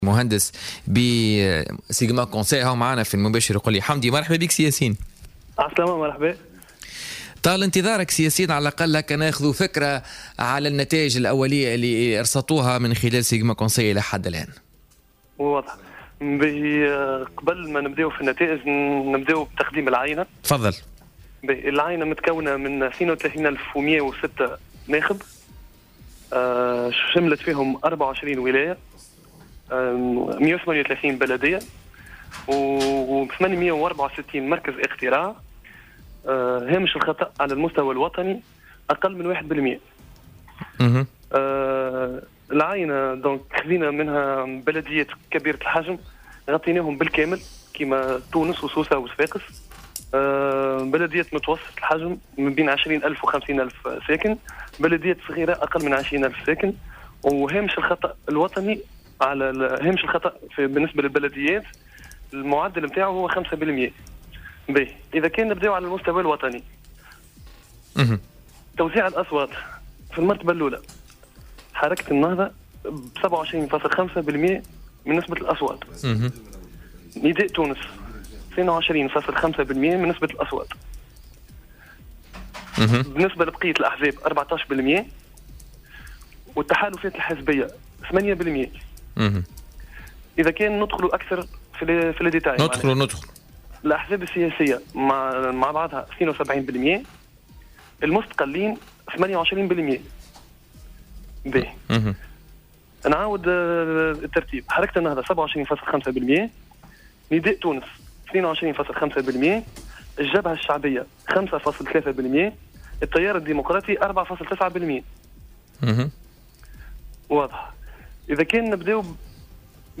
في مداخلة له على الجوهرة اف ام التقديرات...